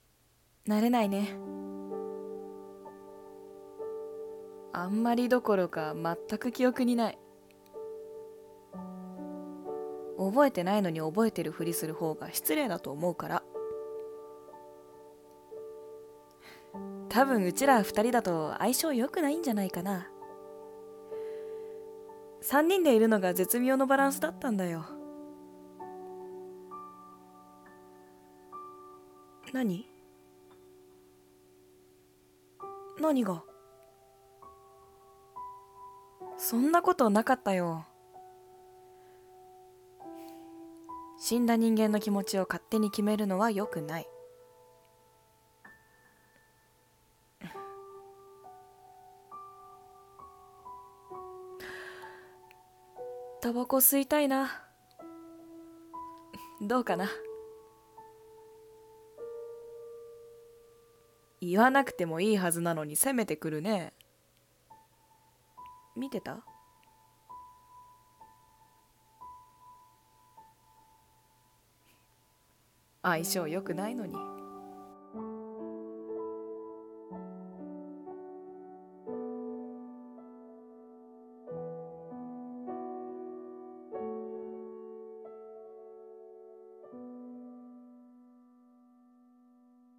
相性、良くないよ。【二人声劇】 演◆